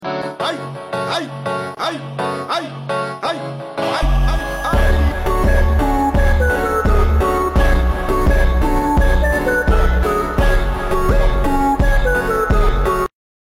ЛУЧШИЙ БИТБОКСЕР МИРА